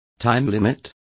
Also find out how plazo is pronounced correctly.